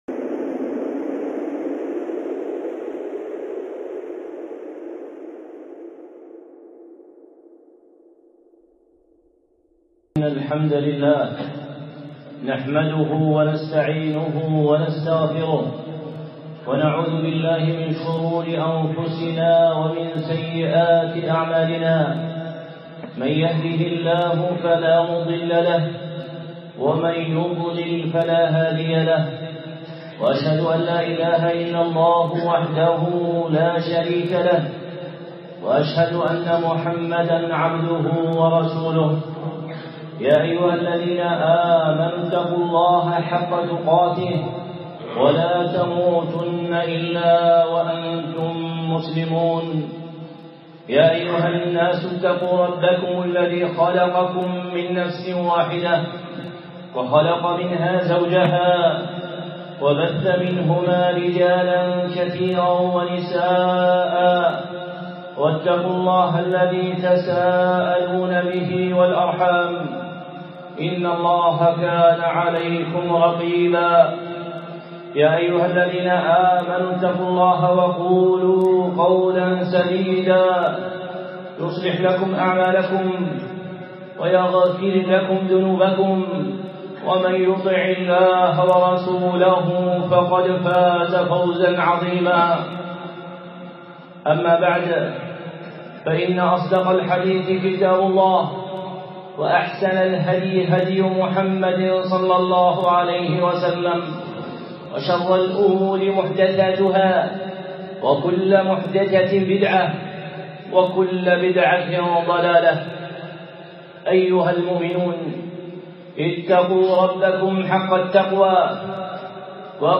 خطبة (إلباس الحق بالباطل في مسألة الحجاب)